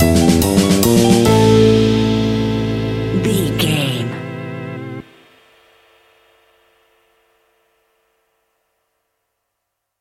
Aeolian/Minor
scary
ominous
haunting
eerie
playful
electric piano
drums
bass guitar
synthesiser
spooky
horror music